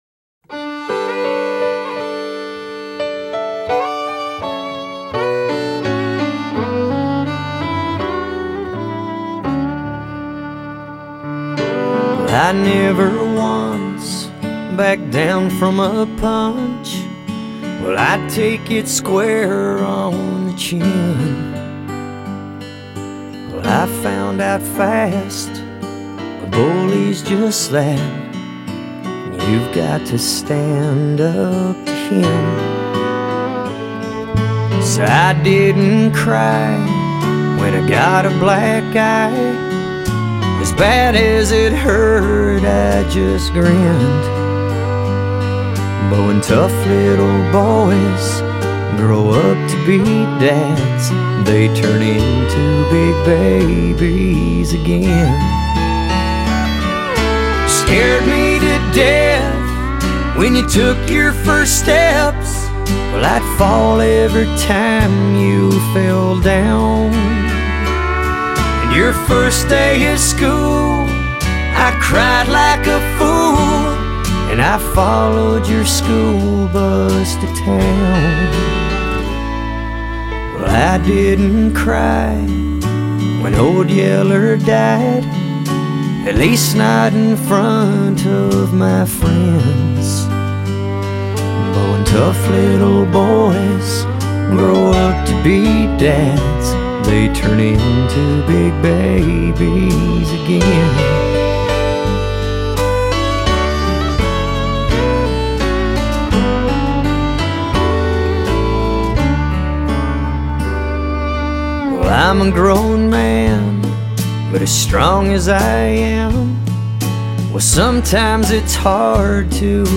曲風類型 : 鄉村搖滾